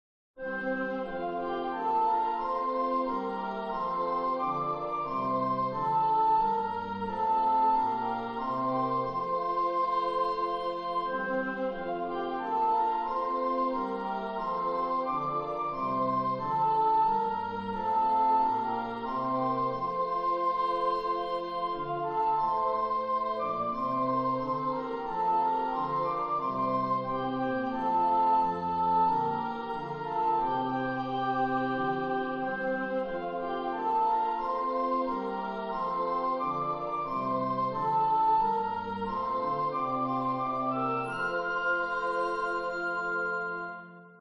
Descant sample
U/2-part Choral For voice, choir, or C instrument.
Christmas Christmas.